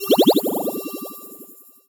potion_bubble_effect_brew_01.wav